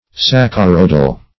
Search Result for " saccharoidal" : The Collaborative International Dictionary of English v.0.48: Saccharoid \Sac"cha*roid\, Saccharoidal \Sac`cha*roid"al\, a. [L. saccharon sugar + -oid: cf. F. saccharo["i]de.]
saccharoidal.mp3